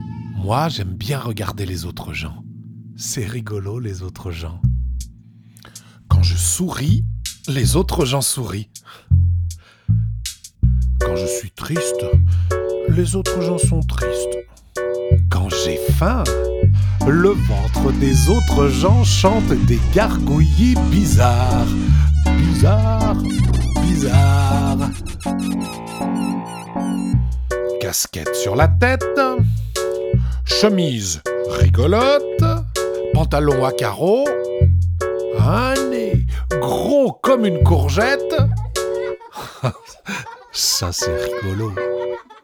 Récit et Chansons Durée